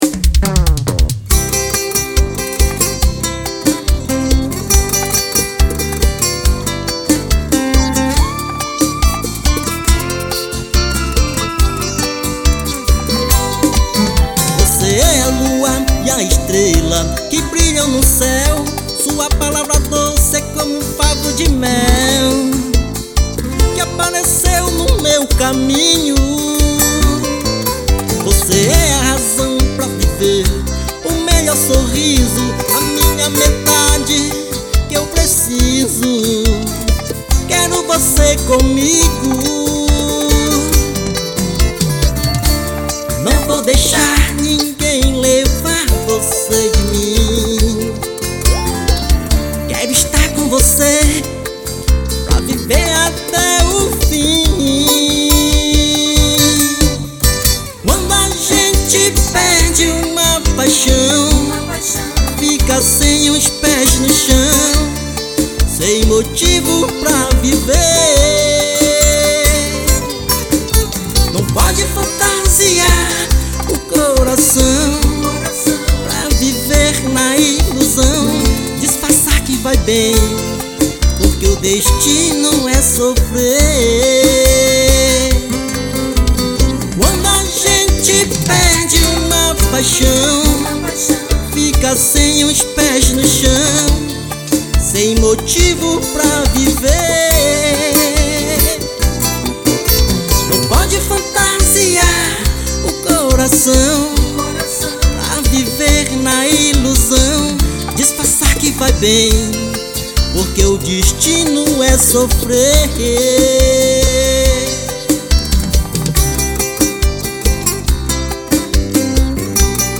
EstiloRomântico